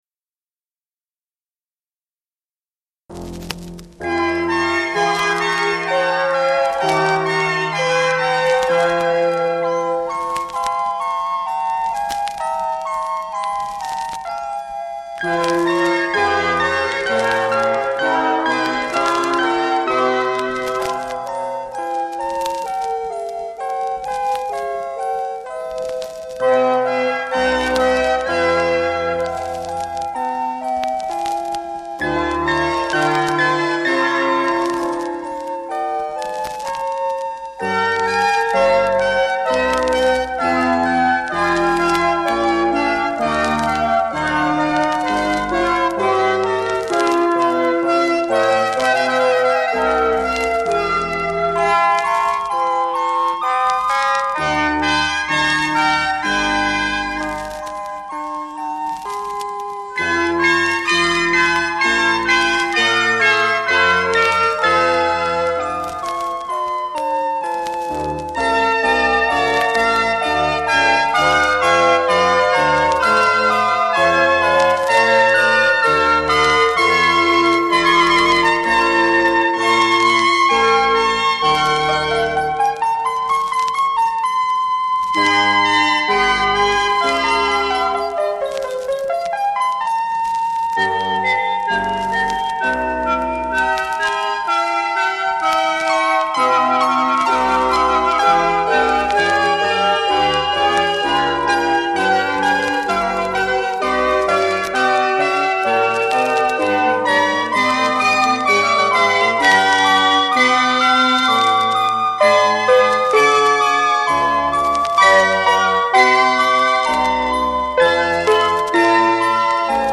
Virtuoso electronic performances